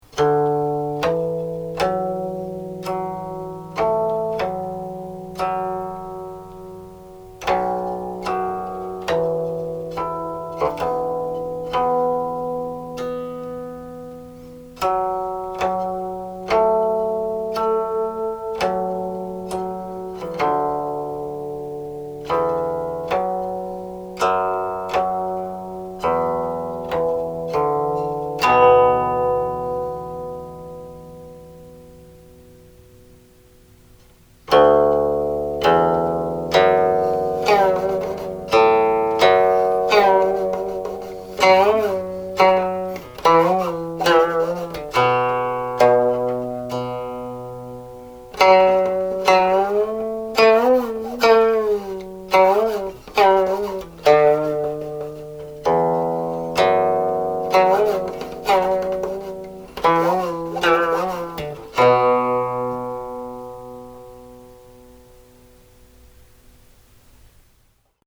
00.00 (Prelude: my arrangement of the melody into harmonics)